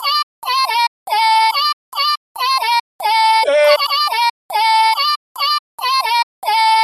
Tag: 140 bpm Trap Loops Vocal Loops 1.15 MB wav Key : Unknown